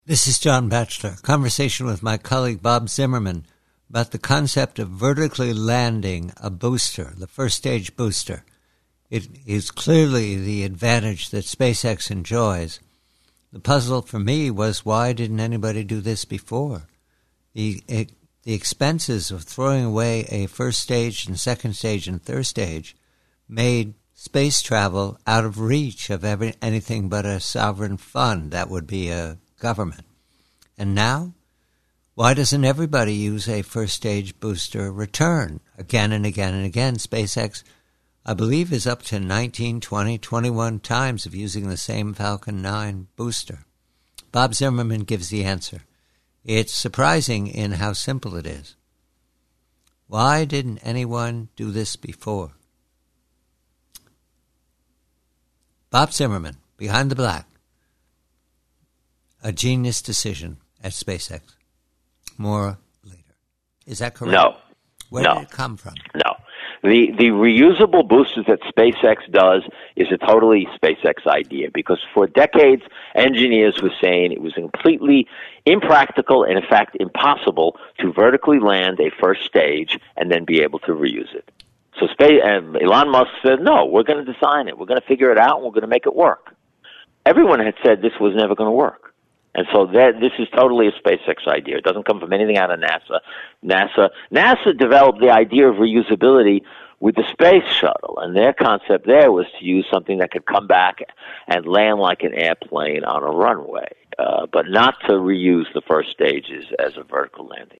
PREVIEW-REUSABLES: Conversation